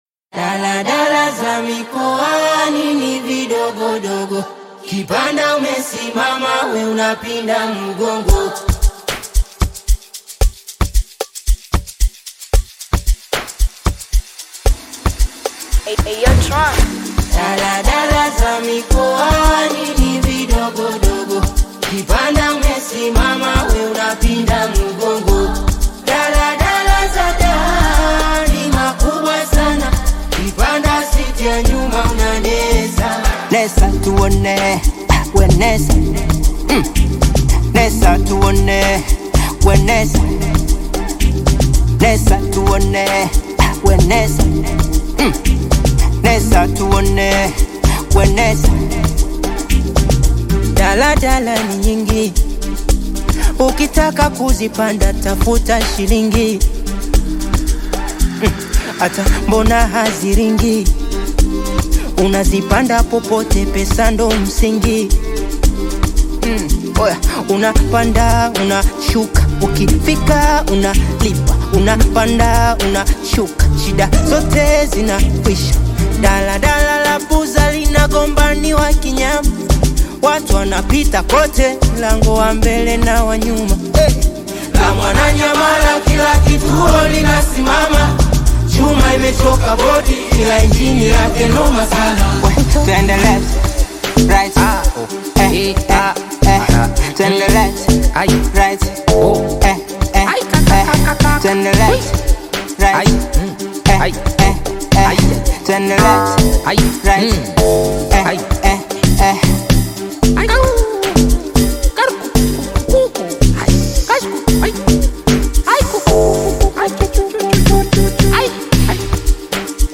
Bongo flava artist
Bongo-piano
African Music